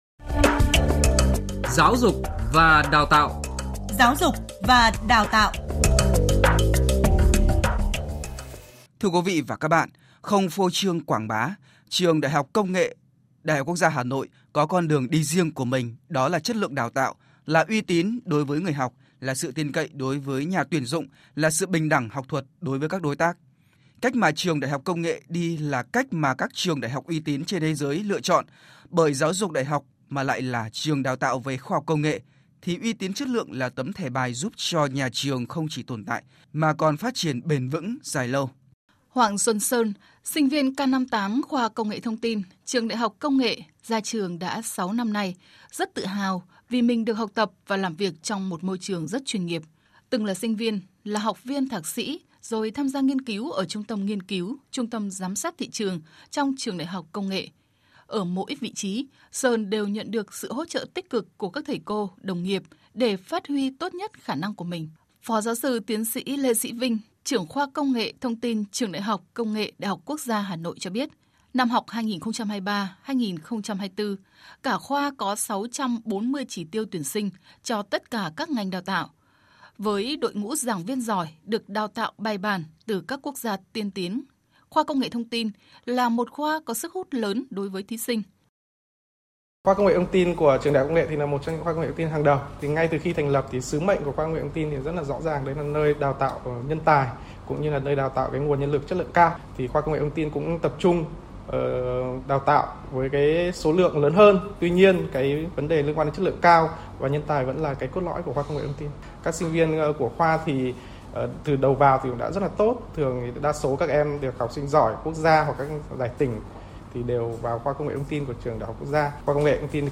cựu sinh viên, sinh viên, đối tác trong nước và quốc tế của trường về thế mạnh đào tạo, nghiên cứu của Trường ĐH Công nghệ được phát sóng tại Chương trình Giáo dục và đào tạo của Đài Tiếng nói Việt Nam, sẽ giúp bạn đọc hiểu thêm về Trường.